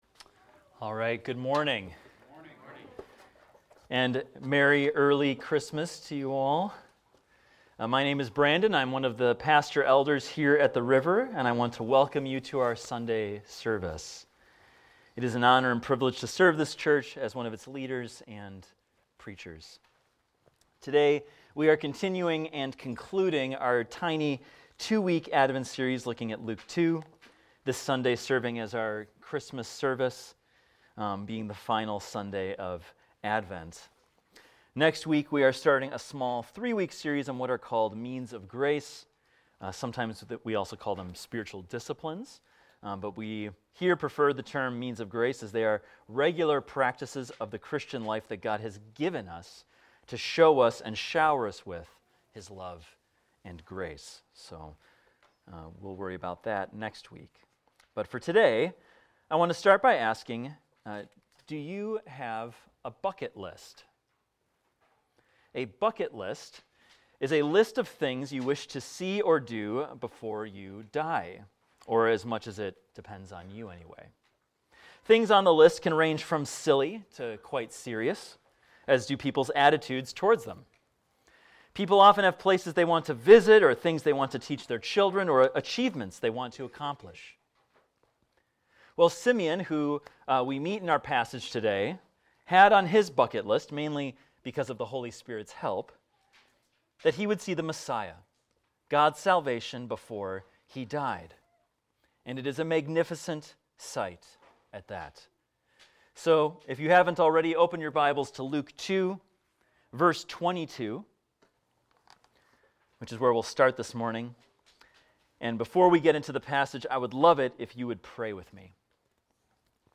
This is a recording of a sermon titled, "The Savior for All!."